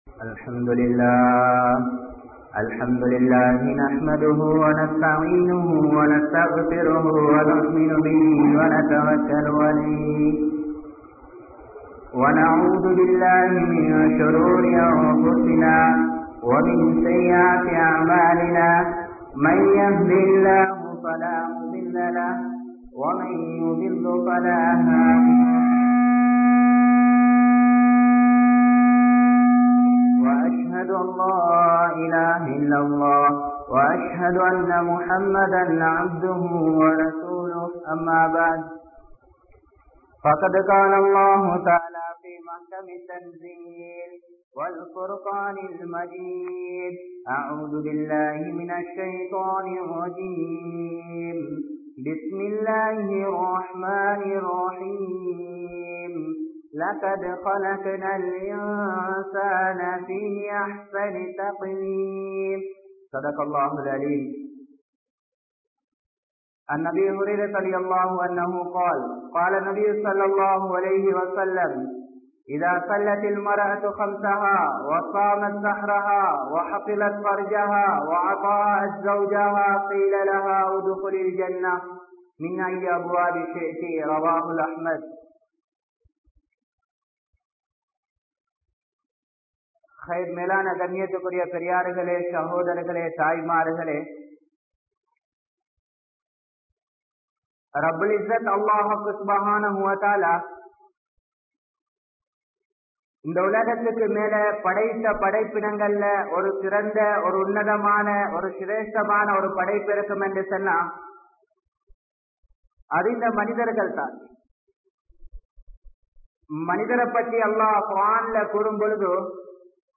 Seeralium Veettu Soolall (சீரழியும் வீட்டு சூழல்) | Audio Bayans | All Ceylon Muslim Youth Community | Addalaichenai